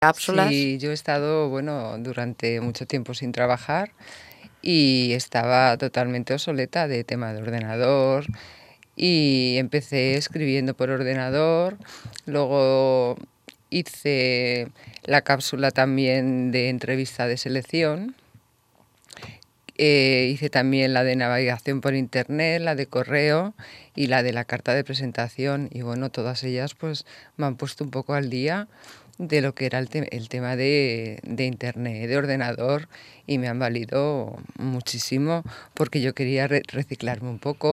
Aquesta setmana hem anat a Ràdio Mollet a explicar les càpsules que oferim als ciutadans i volem compartir amb vosaltres diferents talls de veu que demostren que estem assolint els objectius amb els usuaris que hi assisteixen: